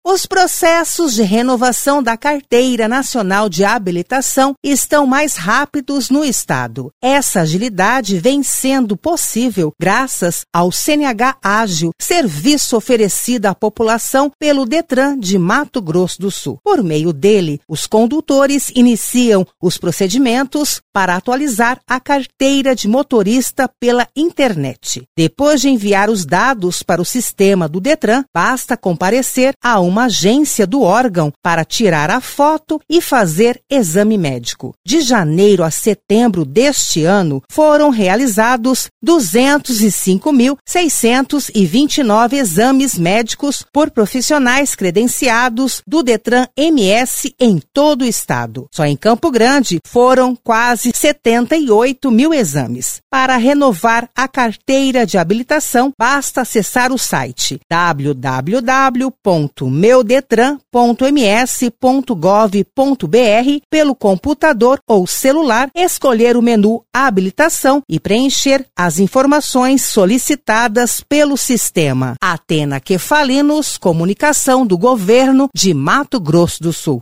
BOLETIM CNH ÁGIL PORTAL